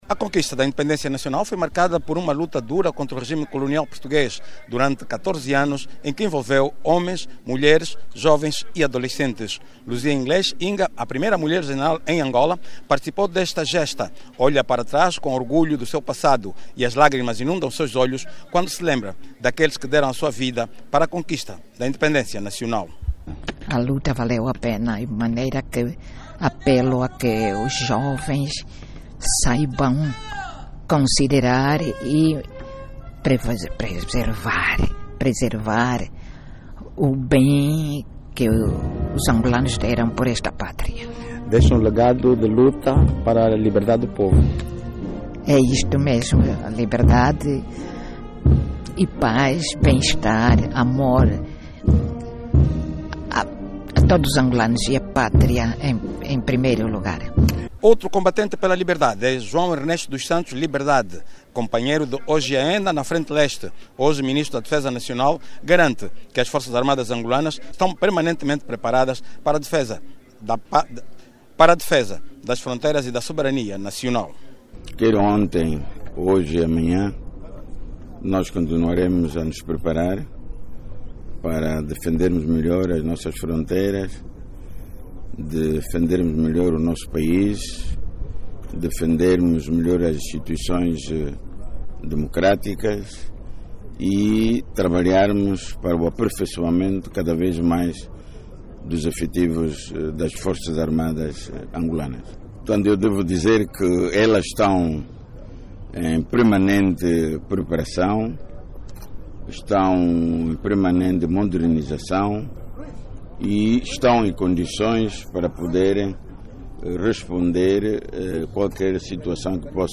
Vários nacionalistas que participaram no acto do hastear da bandeira-monumento destacaram os ganhos alcançados ao longo dos 50 anos de Independência Nacional e defenderam que a juventude deve preservar as conquistas obtidas durante este período.